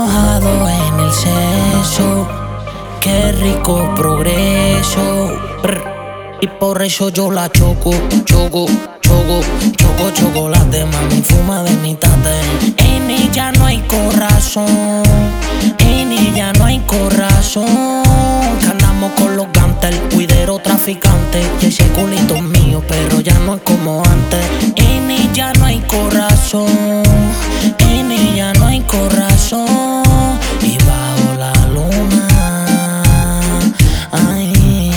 Urbano latino Latin
Жанр: Латино